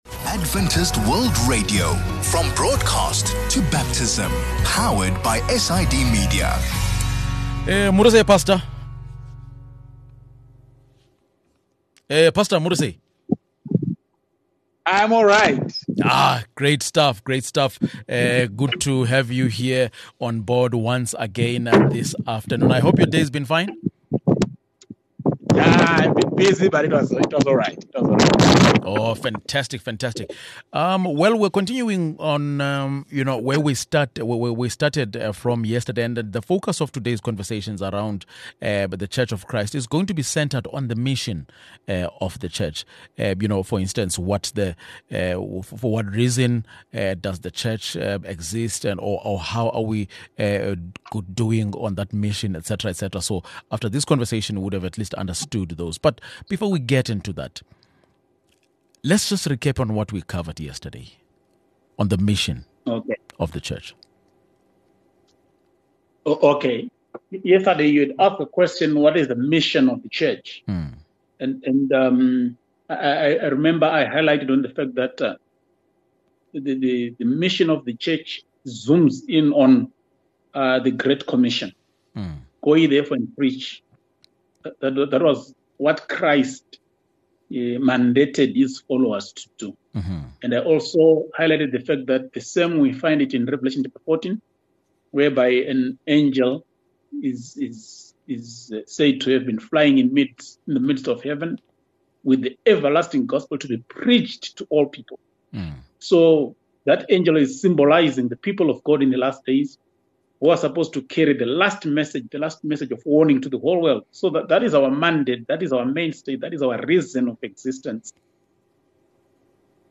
The focus of today’s conversation around the church of Christ is going to be centred on the mission of the church. For what reason does the church even exist?